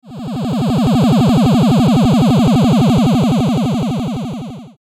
ufo.mp3